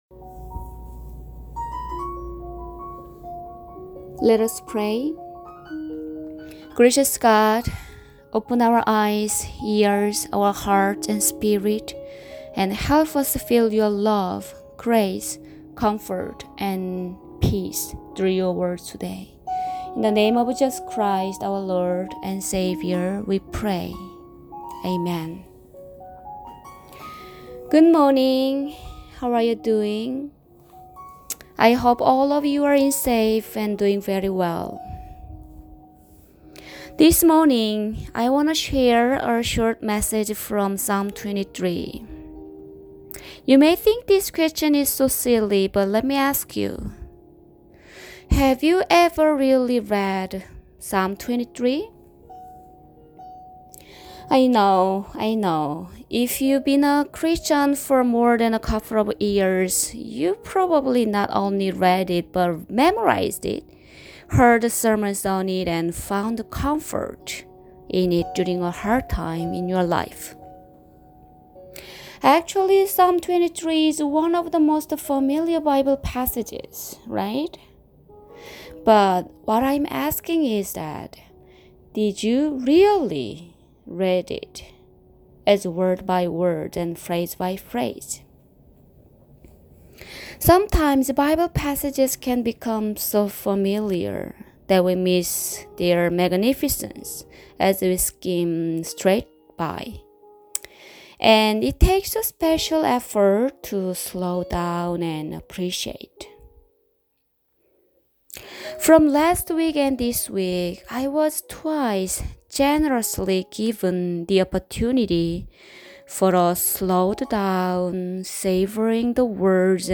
Preaching for March 22, 2020